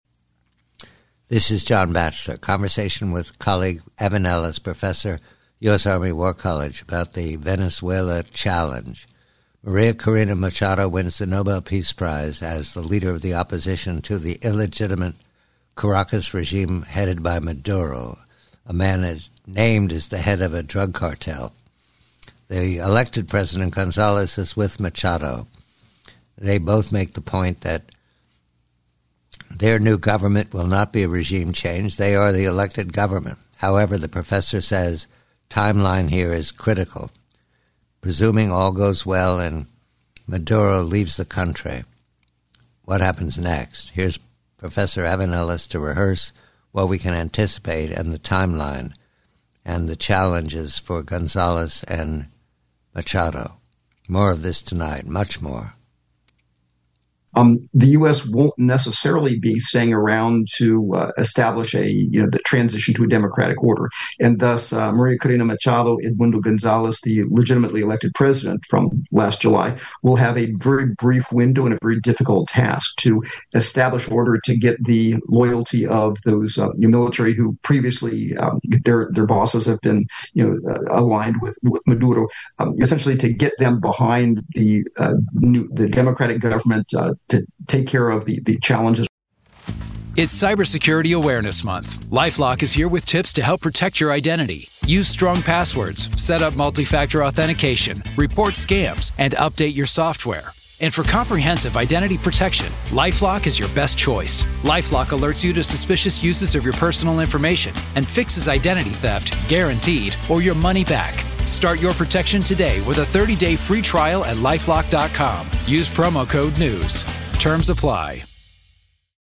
The conversation focuses on the elected president, Edmundo González, and Nobel Peace Prize winner María Corina Machado. Machado and González insist they are the elected government and their transition is not a regime change.